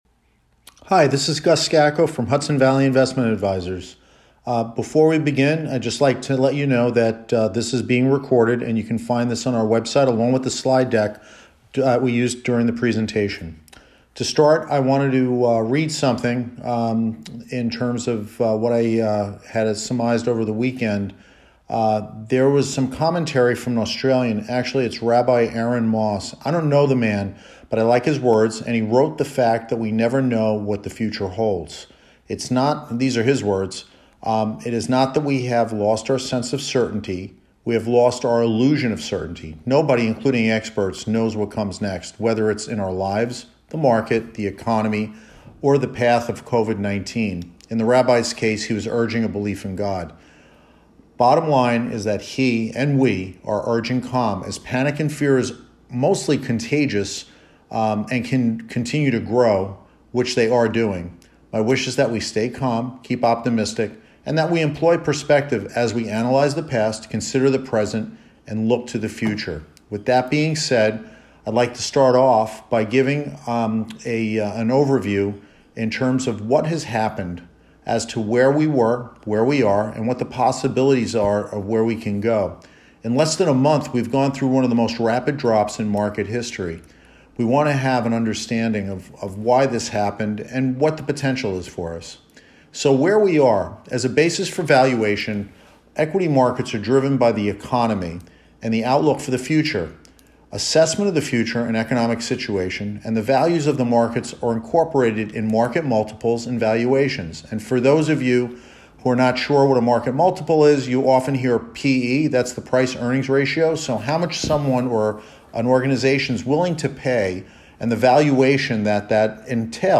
March 23rd COVID-19 Conference Call